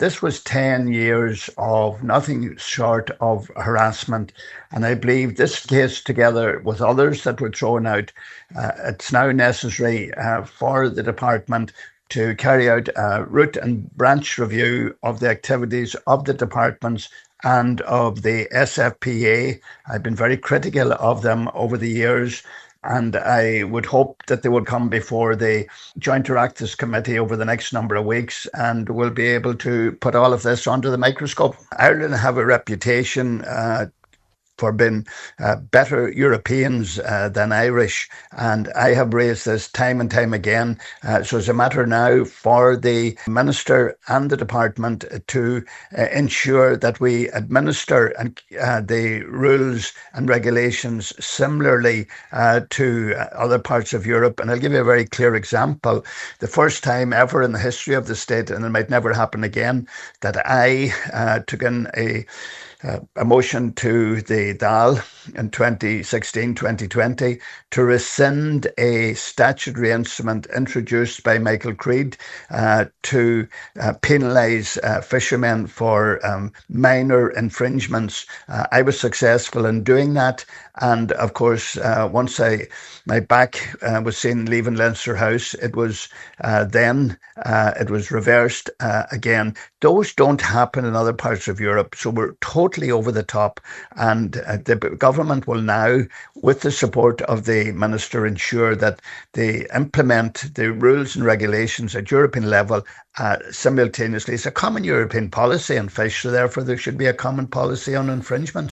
On today’s Nine til Noon Show, he called for a comprehensive reform of the Department of Agriculture, Food and the Marine and its management of fisheries policy.